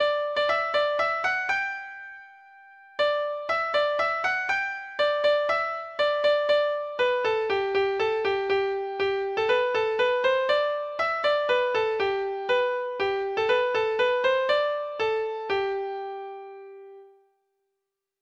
Folk Songs from 'Digital Tradition' Letter T The Golden Vanity (z)
Free Sheet music for Treble Clef Instrument
Traditional Music of unknown author.